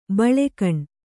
♪ baḷe kaṇ